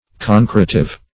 Concretive \Con*cre"tive\, a. Promoting concretion.